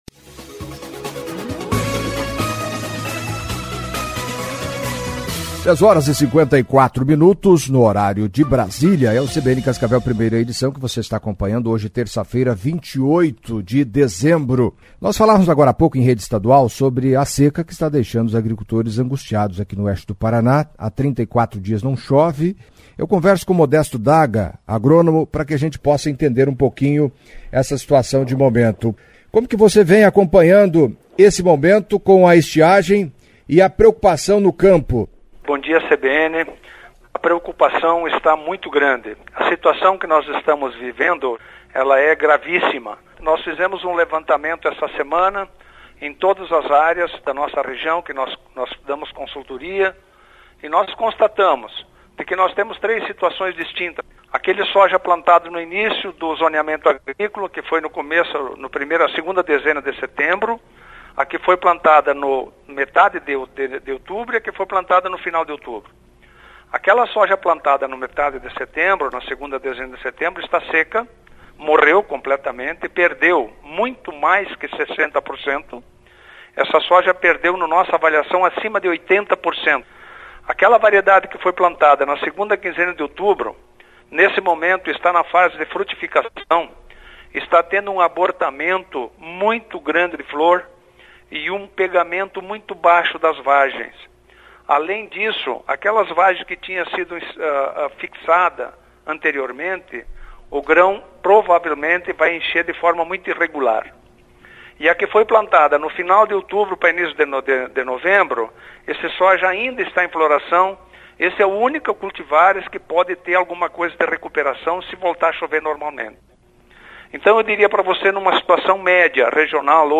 Entrevista à CBN Cascavel nesta terça-feira